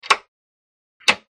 SwitchesBreakerOld PE263802
Switches; Breaker On / Off 2; Older Breaker Style Switch Being Turned On And Then Off Again; Medium Perspective.